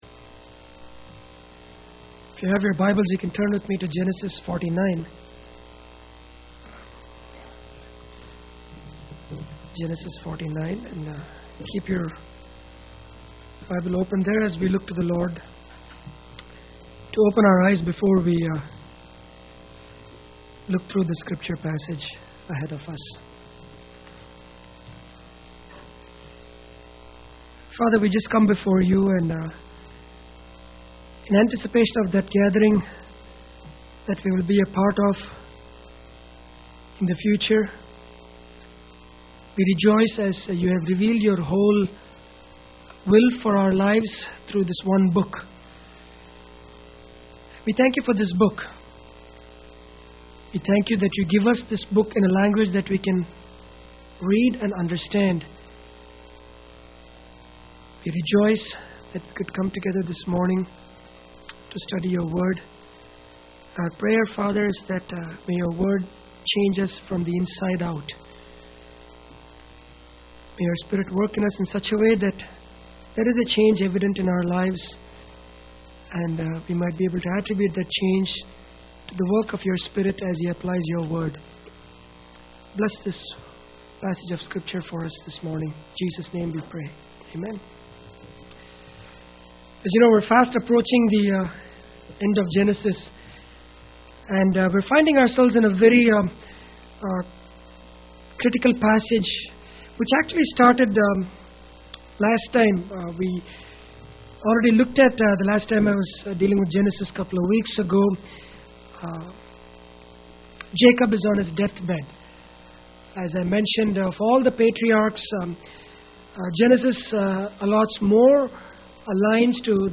Bible Text: Genesis 49:1-4 | Preacher